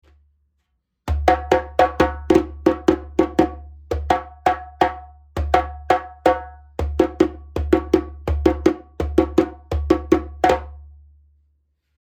ボディ マリ 打面 36cm、高さ61cm、重量7.1kg 木材 メリナ ヤギ皮 マリ（中厚皮） 縦ロープ：ブ
メリナウッドの真骨頂、36センチの大口径でこのサウンドと肉厚ボディーで7.1キロ